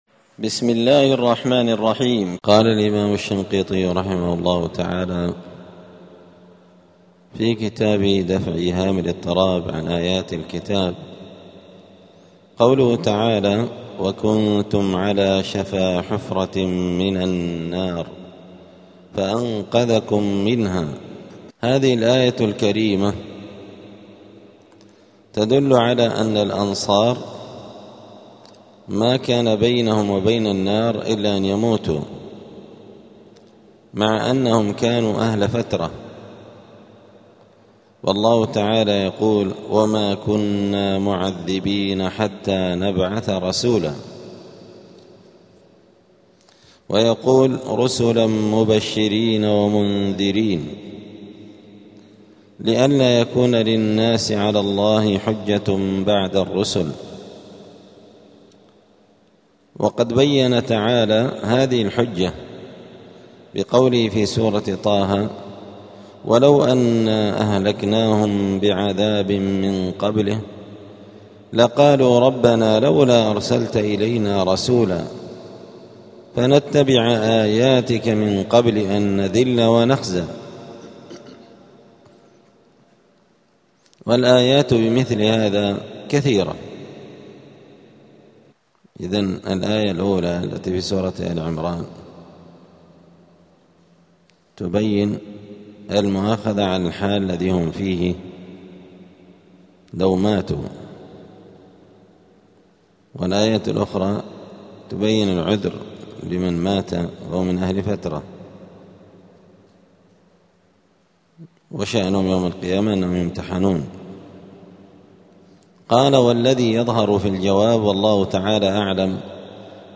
*الدرس التاسع عشر (19) {سورة آل عمران}.*